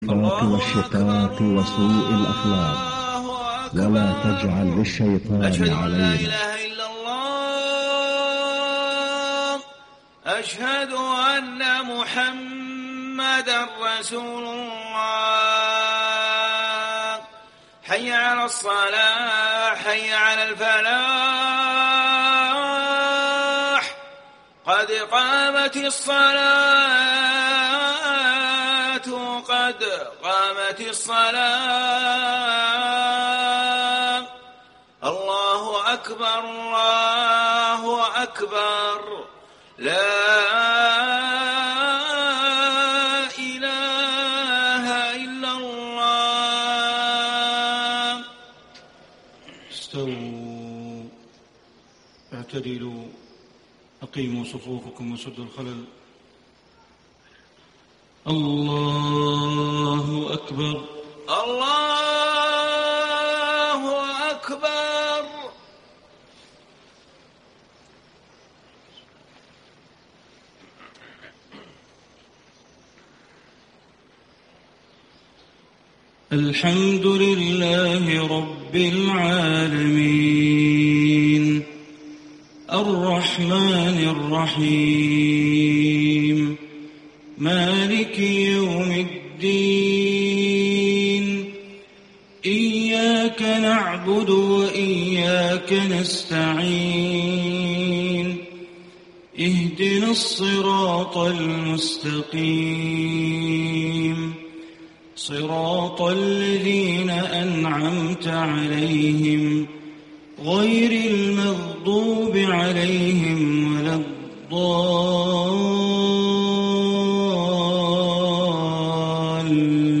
صلاة الفجر 1-2-1435 ماتيسر من سورة يونس > 1435 🕋 > الفروض - تلاوات الحرمين